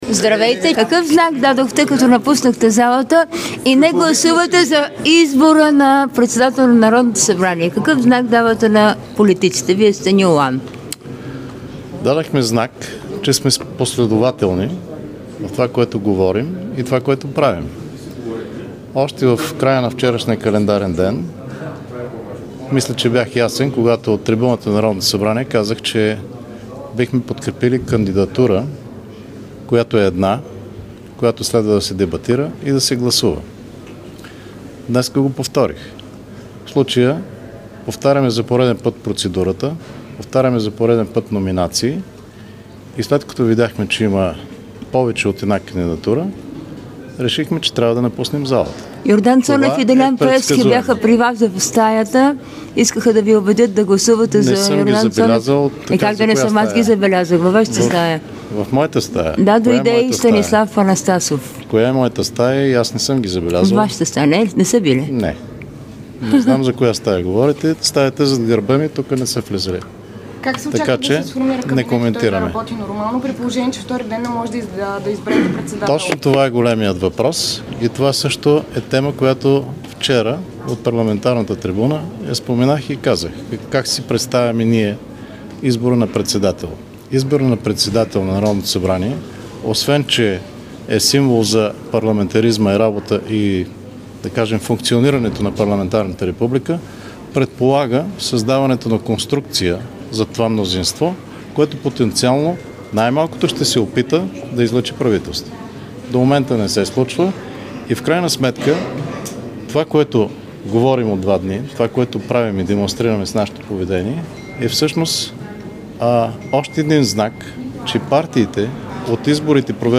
Briefing Stefan Yanev 17 30H 20 10 22